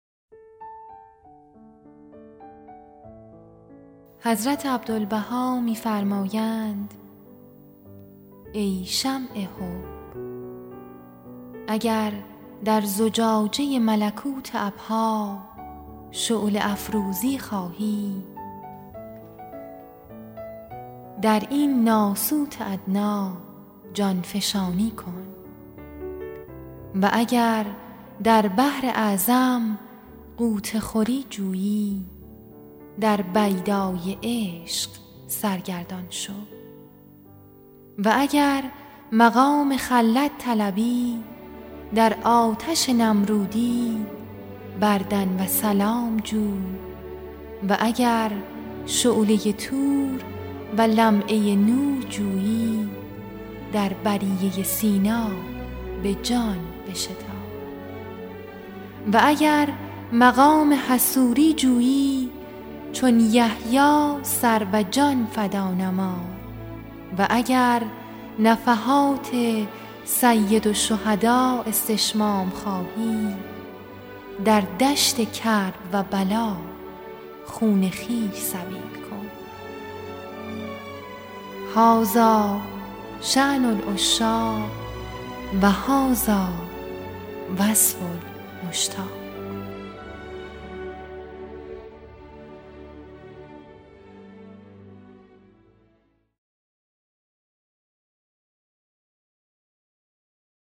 آثار مبارکه بهائی در مورد حضرت سید الشهداء به صورت صوتی همراه با موسیفی